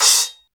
Index of /90_sSampleCDs/Roland L-CD701/CYM_FX Cymbals 1/CYM_Splash menu
CYM 13 CHO0C.wav